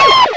cry_not_gible.aif